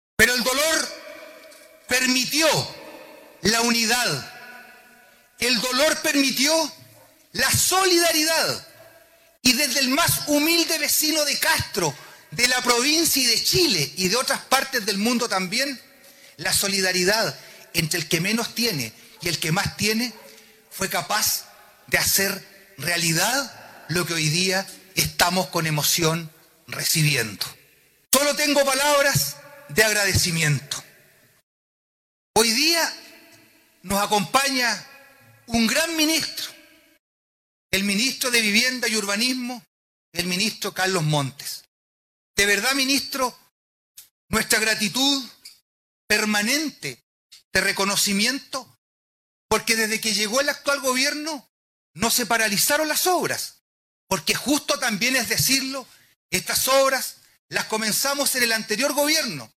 A 11 meses del incendio que afectó a la población Camilo Henríquez en Castro, el mediodía de este jueves, se realizó la entrega de las 49 casas construidas, con mobiliario completo, listas para ser habitadas, las que fueron inauguradas en una ceremonia que contó con la presencia del ministro de Vivienda y Urbanismo, Carlos Montes, autoridades locales y regionales, representantes de Desafío Levantemos Chile, de los gremios salmoneros, y la masiva presencia de los vecinos beneficiados.
En la oportunidad el alcalde de Castro Juan Eduardo Vera, destacó la solidaridad de todos quienes participaron de una u otra forma en la reconstrucción de las viviendas: